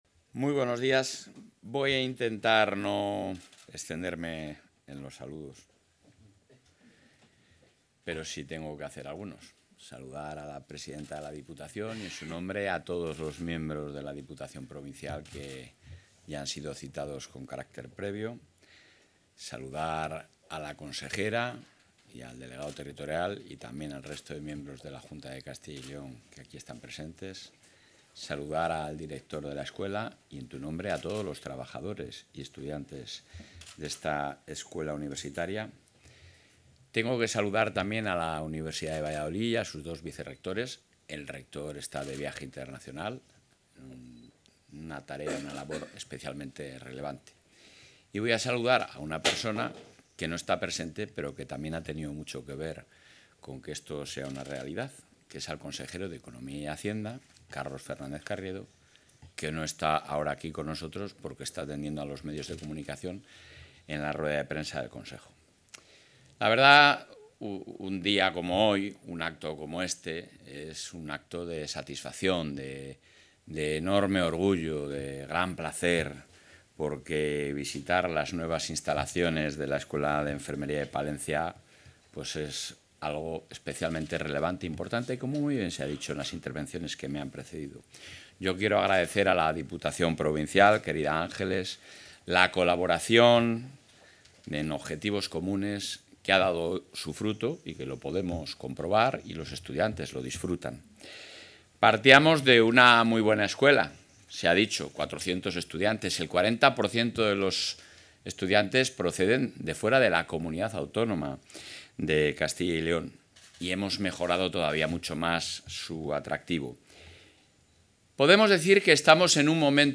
Intervención del presidente.
El presidente del Ejecutivo autonómico ha visitado hoy las nuevas instalaciones de la Escuela Universitaria de Enfermería de Palencia, unas obras de rehabilitación integral que han supuesto una inversión total de más de 7,8 millones de euros cofinanciados a través del Fondo de Transición Justa de la Unión Europea.